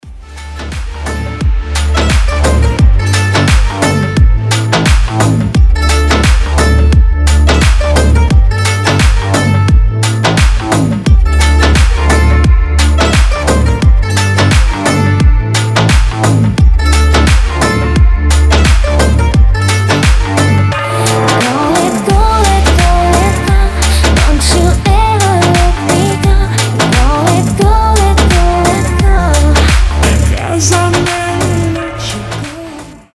• Качество: 320, Stereo
deep house
мелодичные
восточные